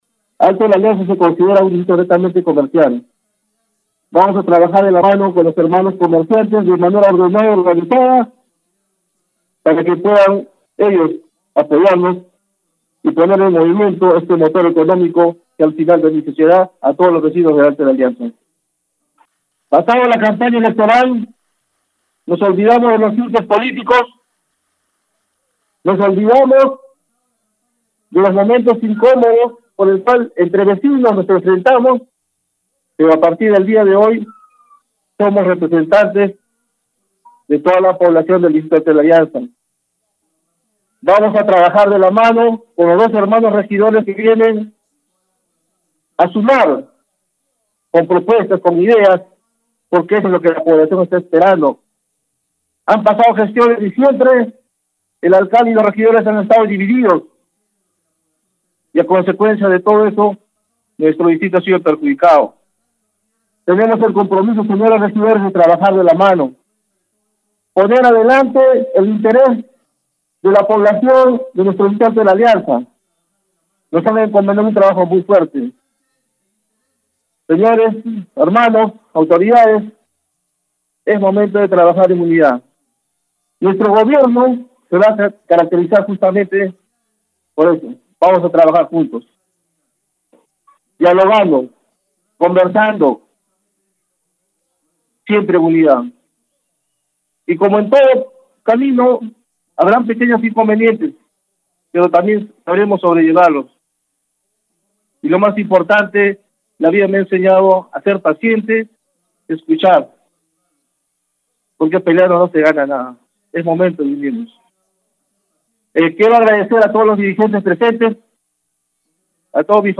Demetrio Cutipa Vilca (organización política Juntos por Tacna) juró al cargo de alcalde electo del distrito Alto de la Alianza en ceremonia realizada minutos después de las 9:00 a. m.  de este 1 de enero en el auditorio del centro cultural Francisco de Paula González Vigil, del municipio distrital.